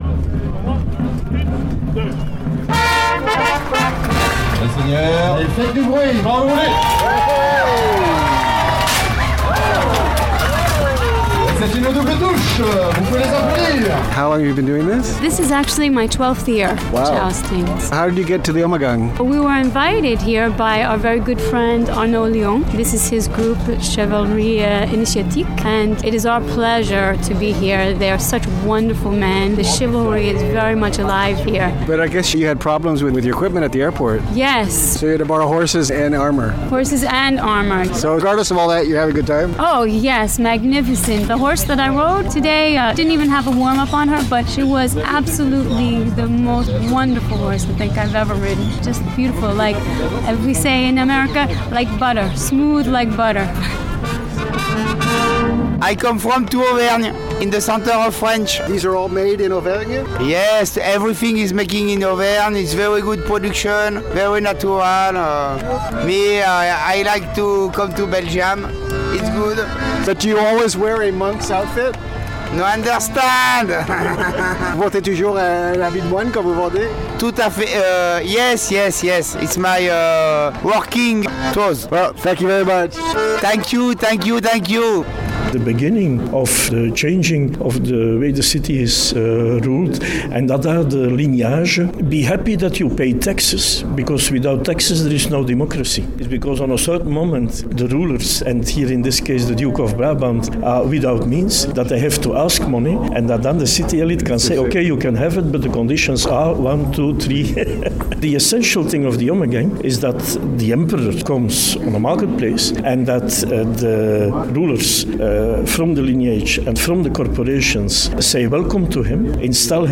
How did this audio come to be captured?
Here are moments from the last two Ommegangs including a visit by American jousters.